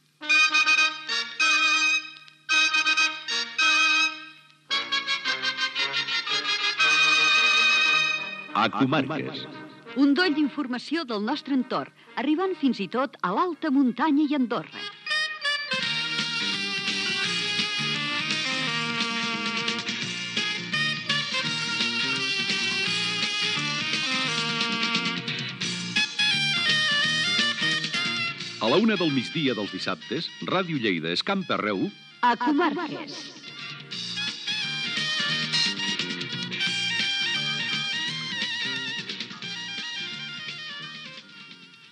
Identificació del programa